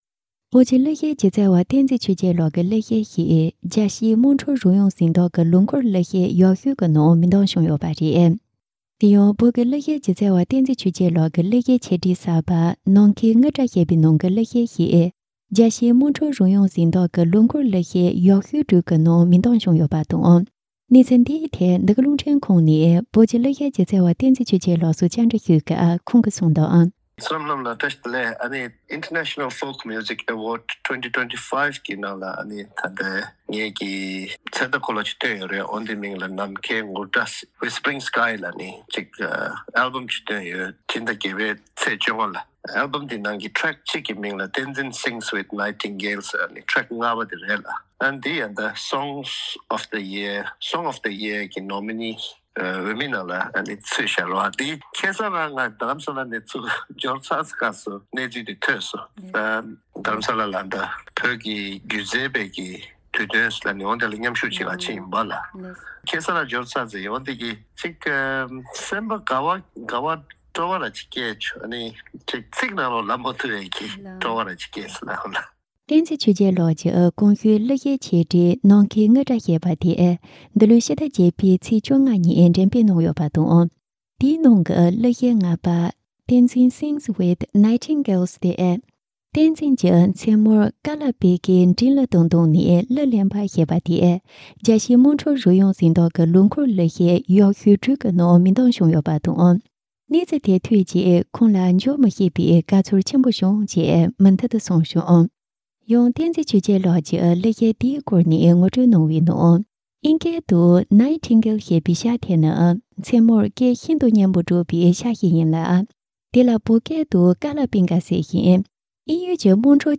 གནས་ཚུལ་བཅར་འདྲི་དང་ཕྱོགས་བསྡུས་ཞུས་པར་གསན་རོགས།